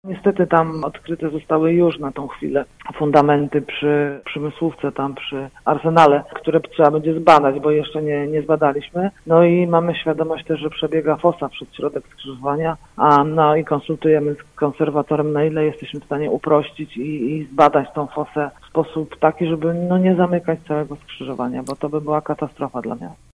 Mówi wiceprezydent Agnieszka Surmacz: